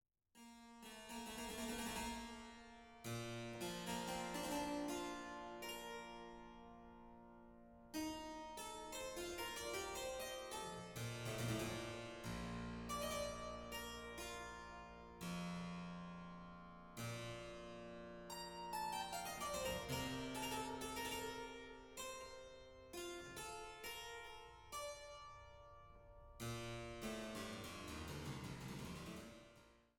Sopran
Traversflöte
Viola da gamba
Cembalo